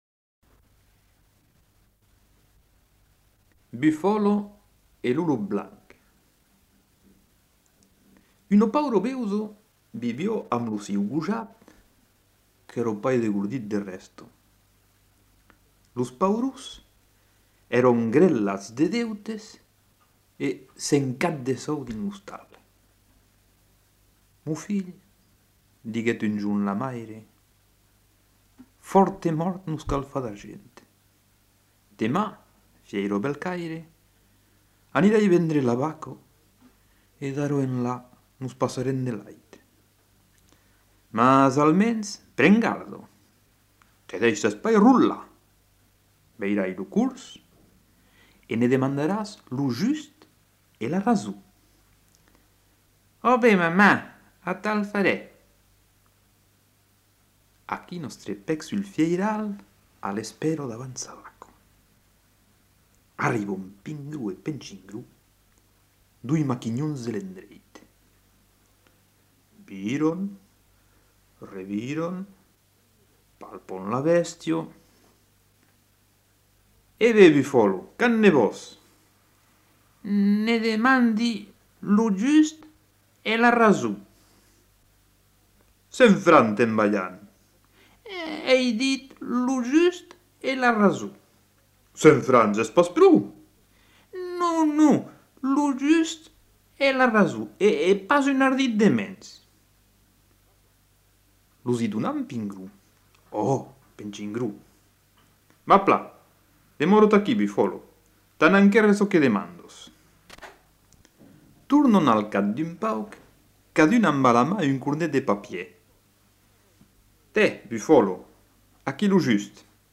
Genre : conte-légende-récit
Type de voix : voix d'homme Production du son : parlé